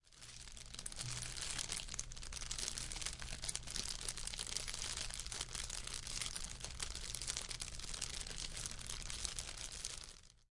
淋浴
描述：浴室浴缸淋浴记录使用冷水
标签： 淋浴
声道立体声